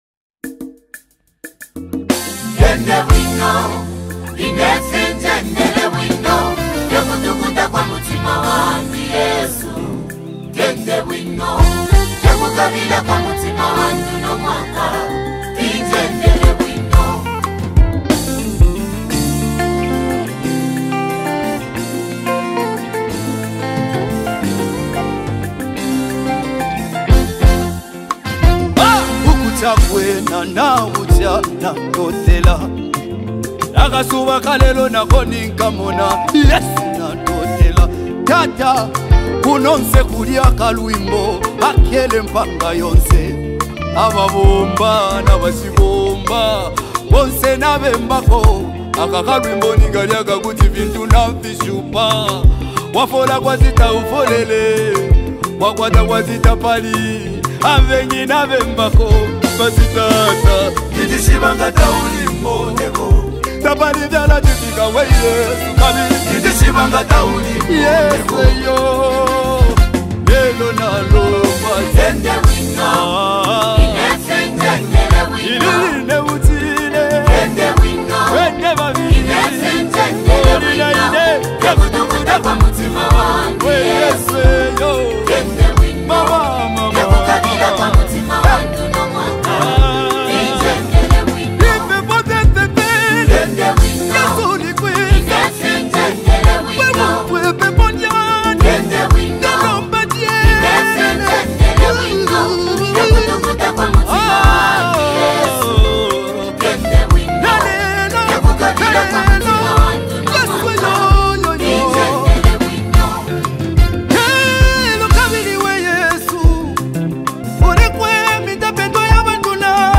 uplifting gospel track